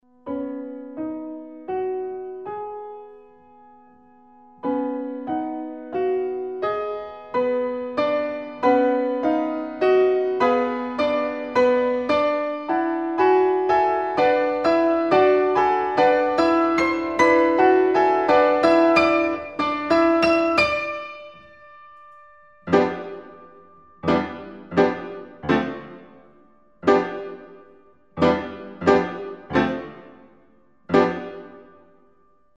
Piano Music
piano